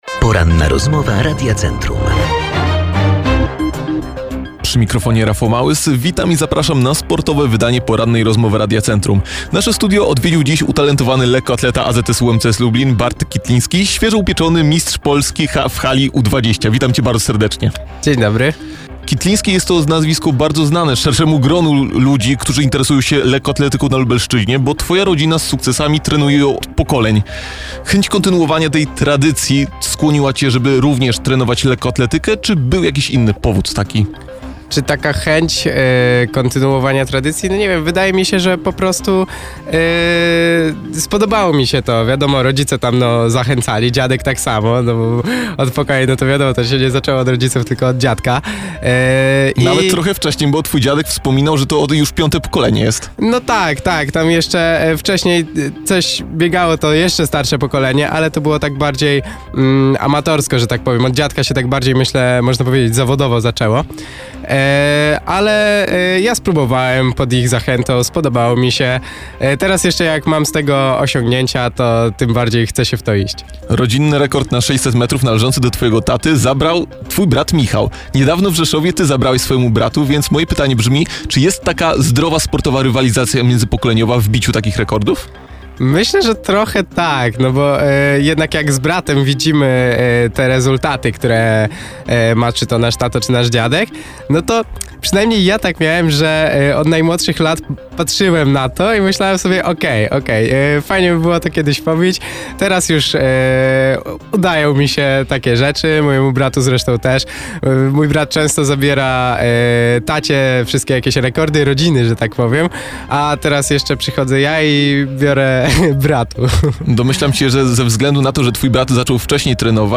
Rozmowa-po-edycji.mp3